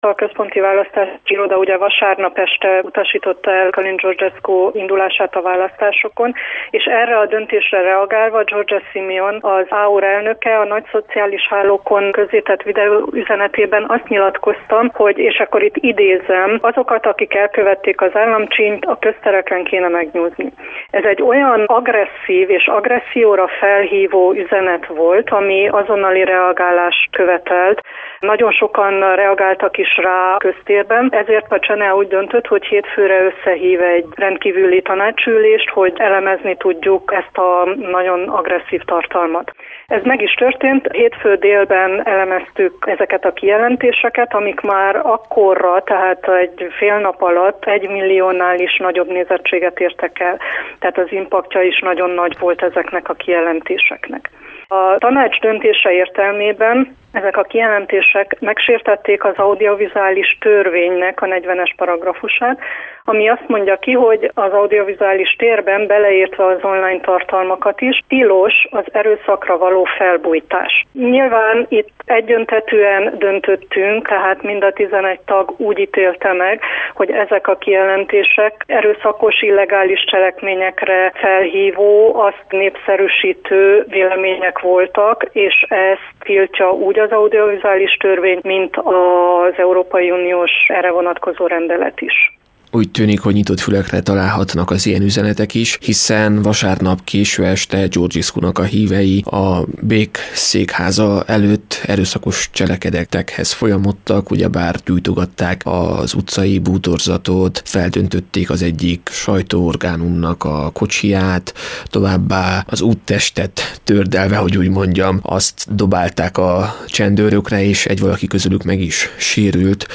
„Ez egy olyan agresszív és agresszióra felhívó üzenet volt, ami azonnali reagálást követelt” – hangsúlyozta a Kolozsvári Rádiónak adott interjújában Borsos Orsolya, az Országos Audiovizuális Tanács tagja.